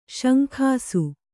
♪ śankhāsu